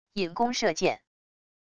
引弓射箭wav音频